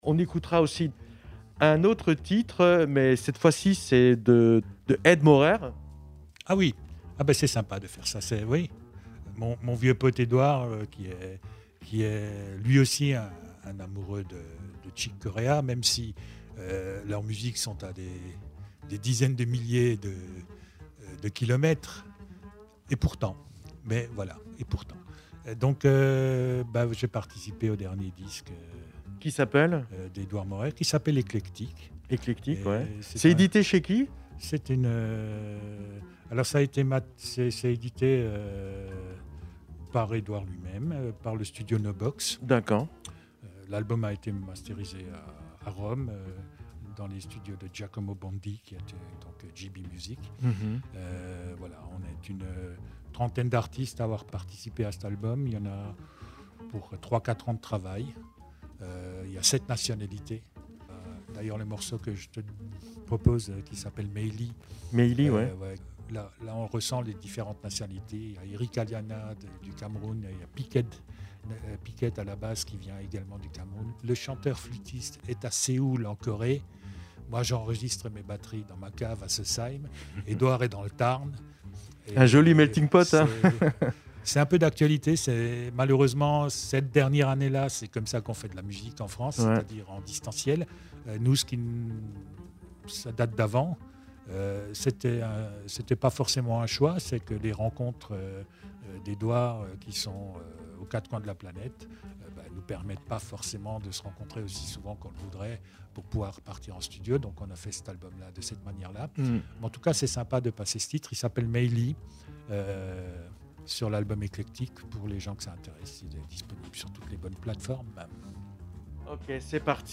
Une interview faite au téléphone